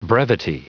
Prononciation du mot brevity en anglais (fichier audio)
Prononciation du mot : brevity